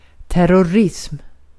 Uttal
Uttal Okänd accent: IPA: /tær.ɔrˈɪsm/ IPA: /tær.ʊrˈɪsm/ Ordet hittades på dessa språk: svenska Översättning Substantiv 1. terörizm Andra/okänd 2. yıldırıcılık Artikel: en .